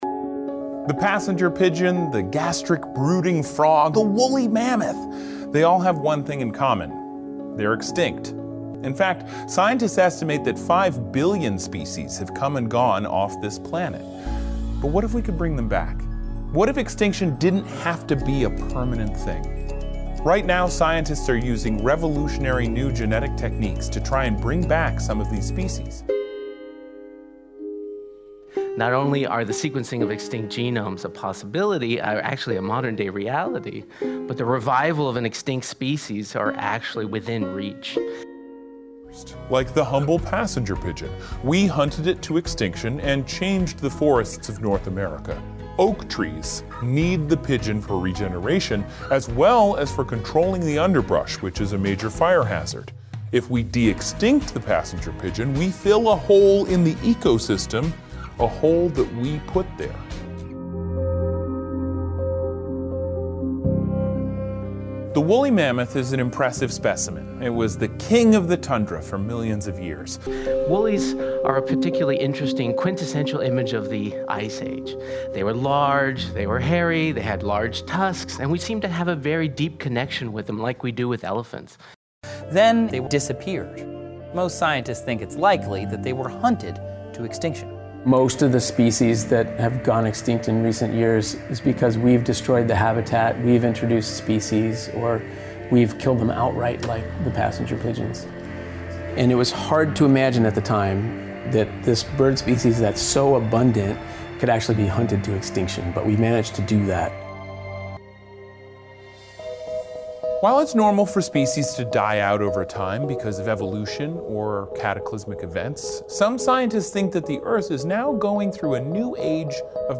Credits: This clip includes audio from: How Close Are We to Resurrecting Extinct Species Youtube clip: Seeker, Series 1, Episode 3. Video from Revive and Restore (no longer on their website) featuring the voice of Stewart Brand.